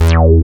75.08 BASS.wav